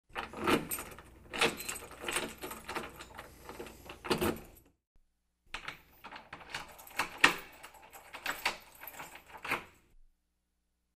Звуки поворота ключа
Звук открывания двери ключом